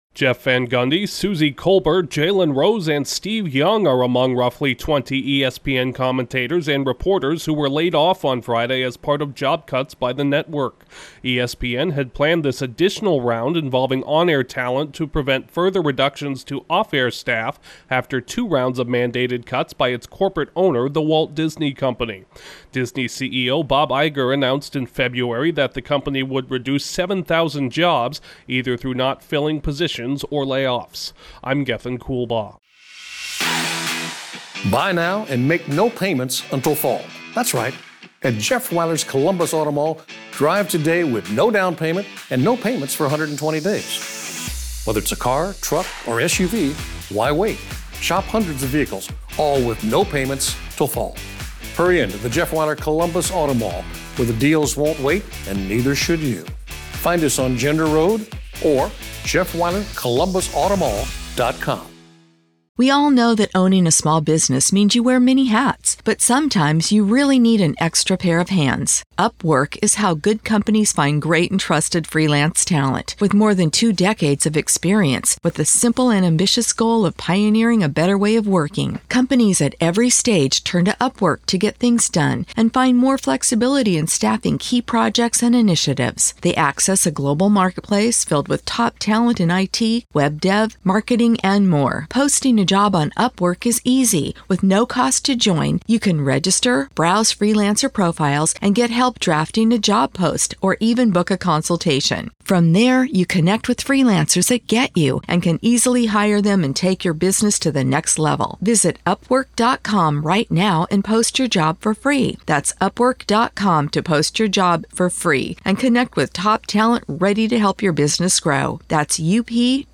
Some well-known sports media figures are among the latest cuts at ESPN. Correspondent